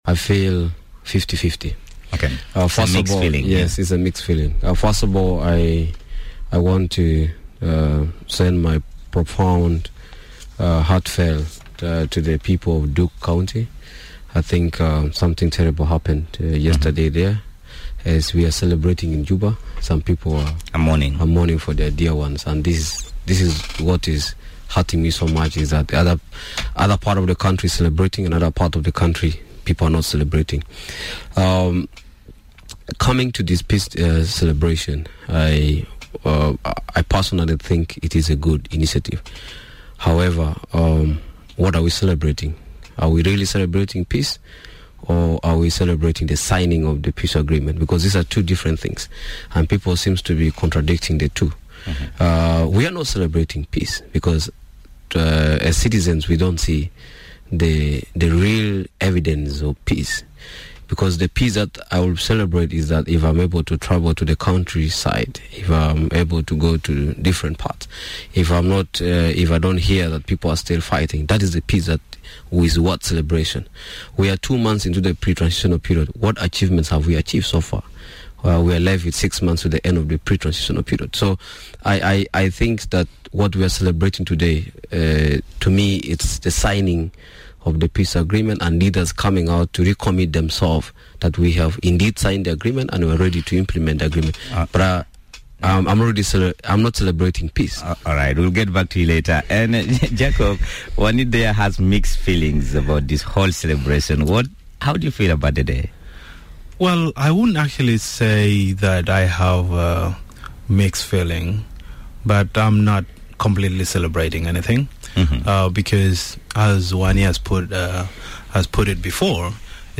Discussion on Peace Day - What will it take for South Sudan to gain Sustainable Peace